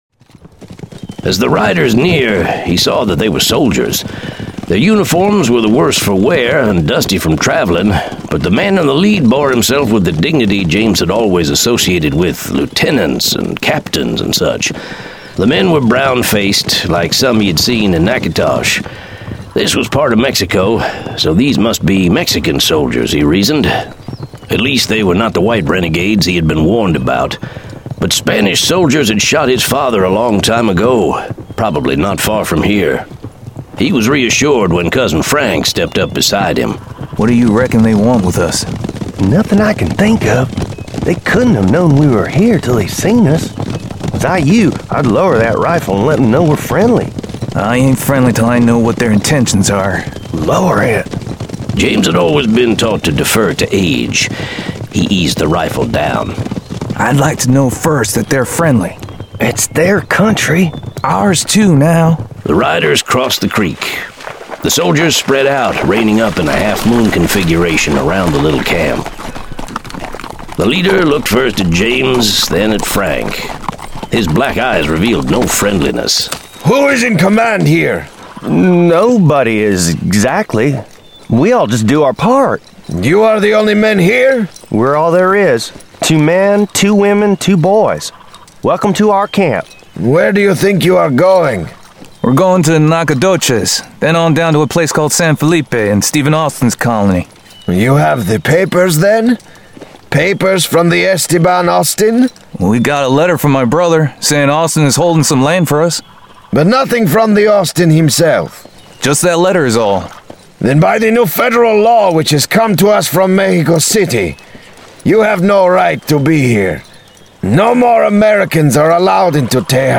Sons of Texas 3: The Rebels 1 of 2 [Dramatized Adaptation]